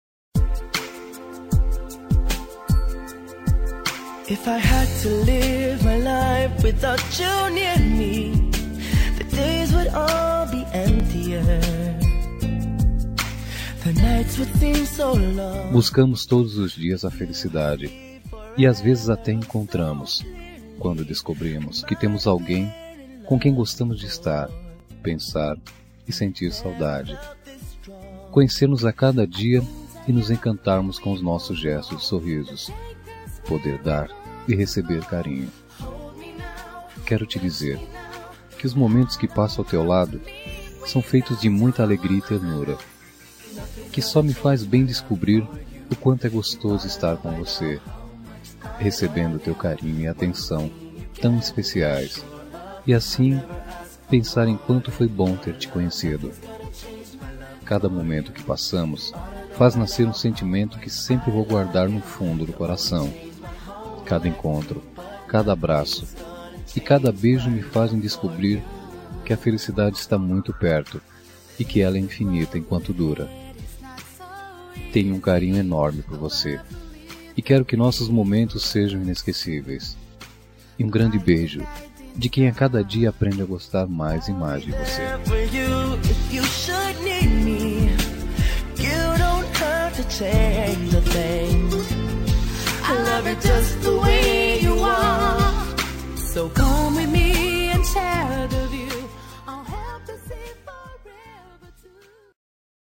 Telemensagem Ficante – Voz Masculina – Cód: 5454